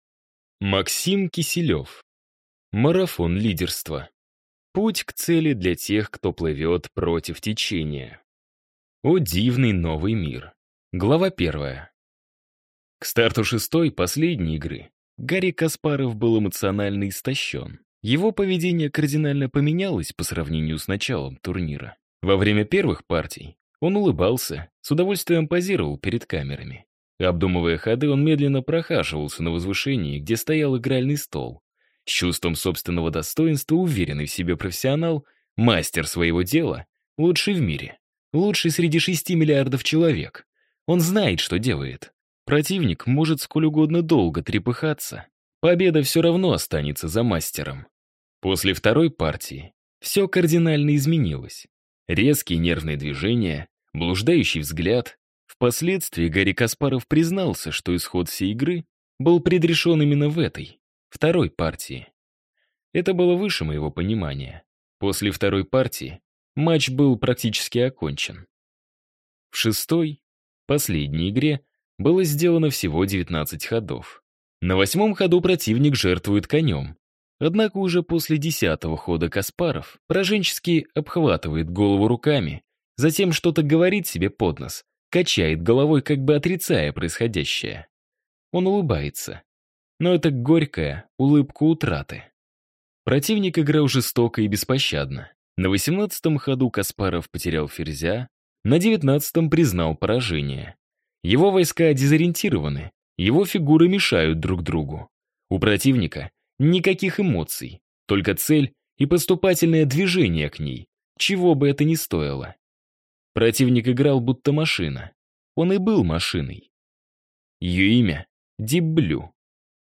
Аудиокнига Марафон лидерства. Путь к цели для тех, кто плывет против течения | Библиотека аудиокниг